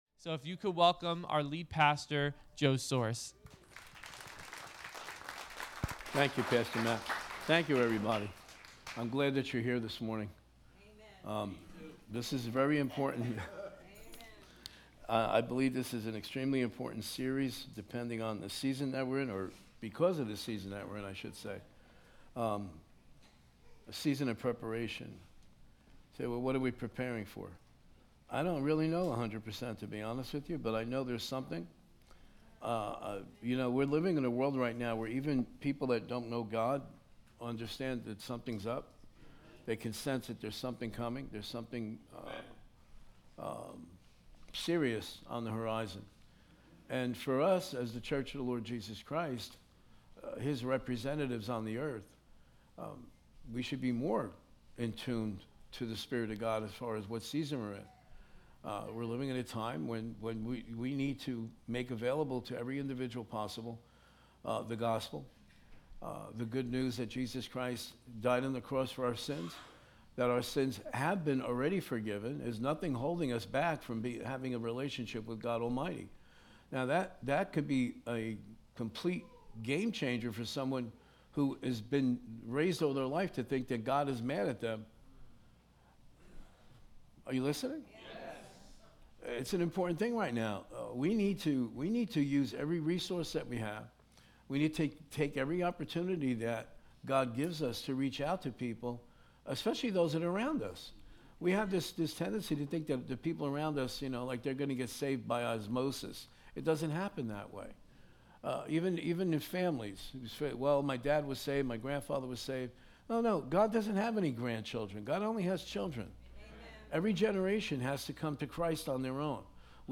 Sunday 9am Service